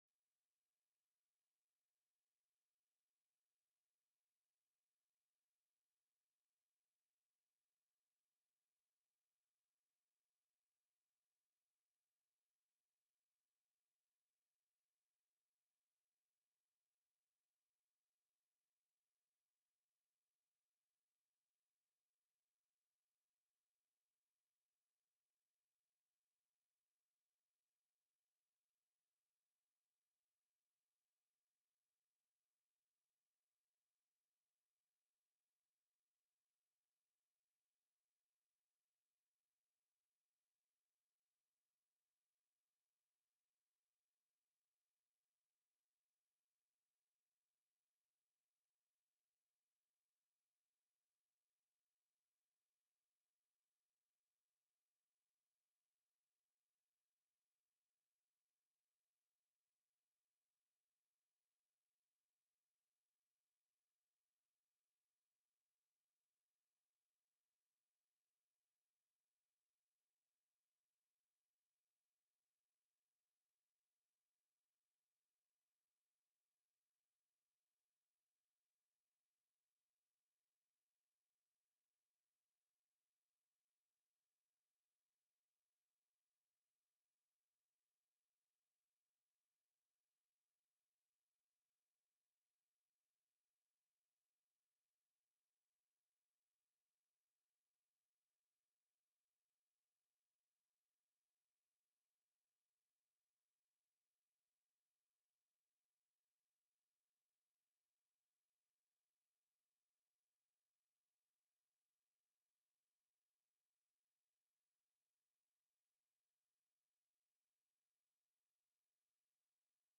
The audio recordings are captured by our records offices as the official record of the meeting and will have more accurate timestamps.
HB 284 TAX COMPACT; SALES TAX; OIL & GAS TAX TELECONFERENCED